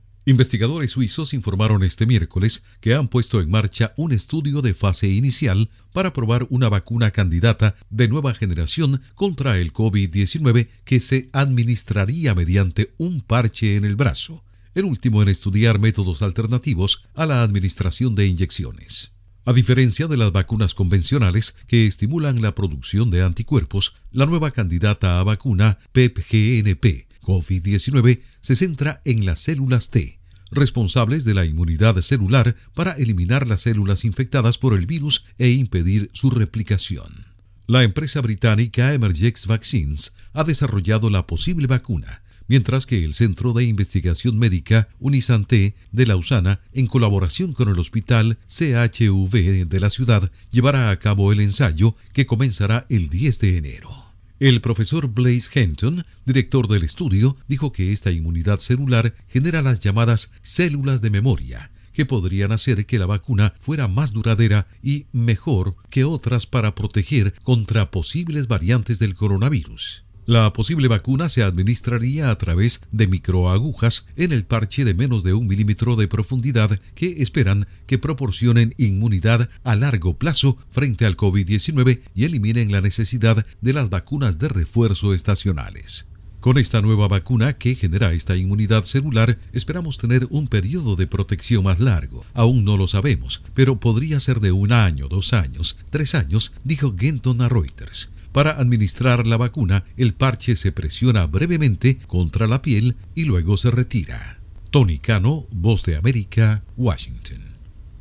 Investigadores suizos inician ensayo de vacuna "parche" contra el COVID-19. Informa desde la Voz de América en Washington